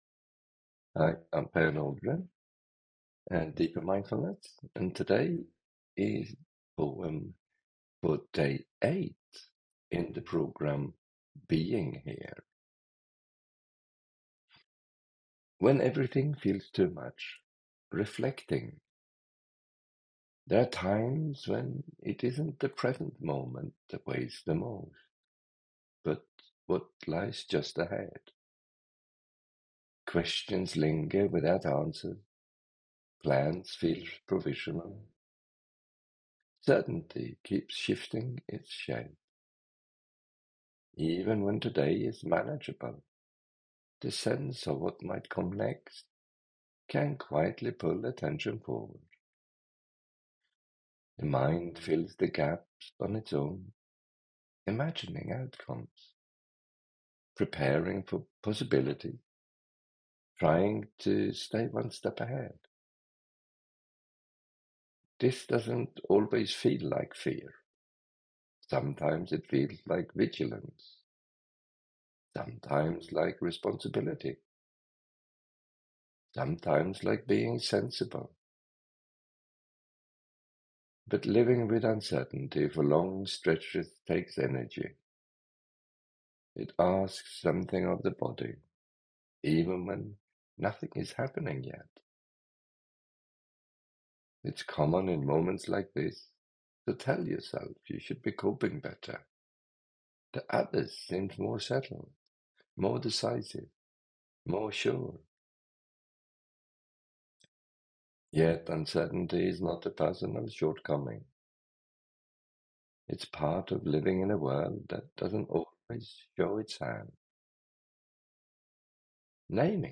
Being-Here-Poem-Day-8.mp3